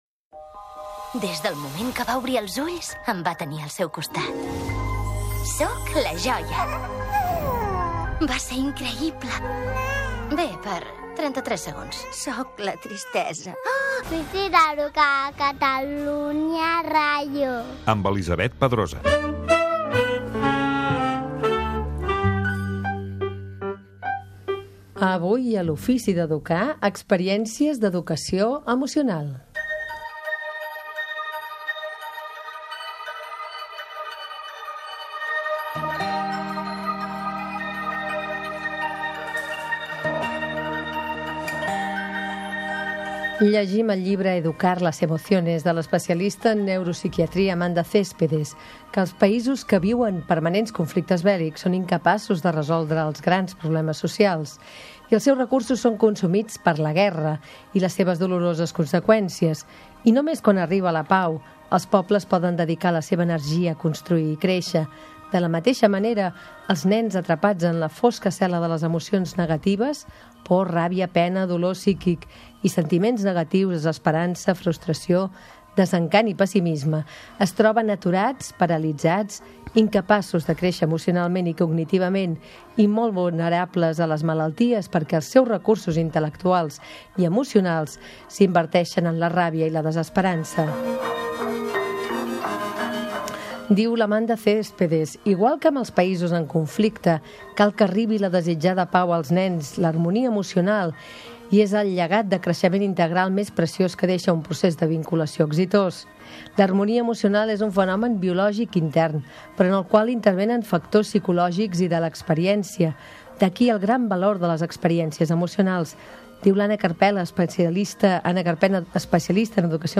"L'holandès errant": roda de premsa al Liceu - 08.04.2007